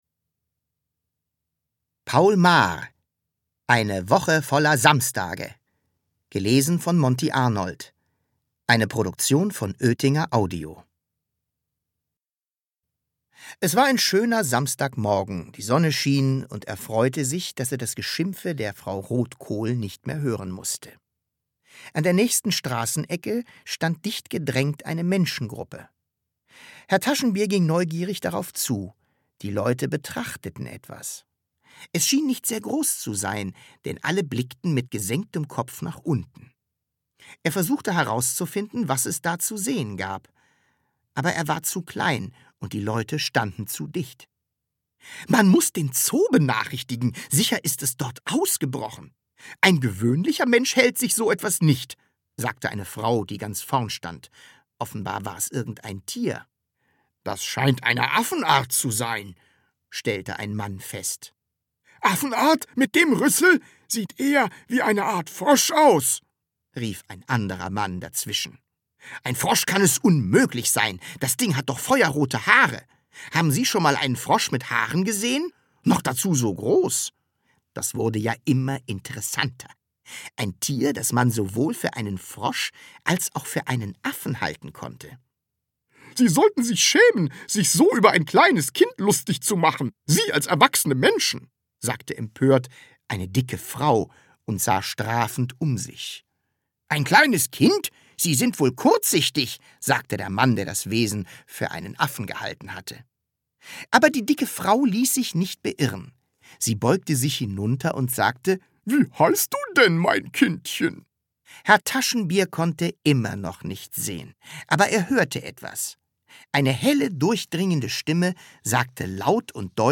Hörbuch: Das Sams 1.